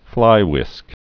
(flīwĭsk, -hwĭsk)